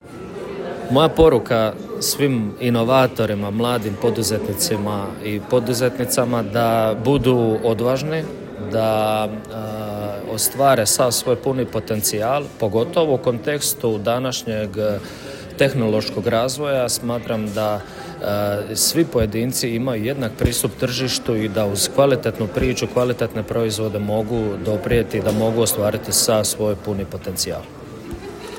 Upravo je to pitanje otvorio prvi panel konferencije Evolution Next Level u Zadru, simbolično nazvan „Savršena temperatura mora?“, koji je okupio predstavnike europskih institucija, gospodarstva, investitora i regionalne politike.
Josip-Bilaver-panel.m4a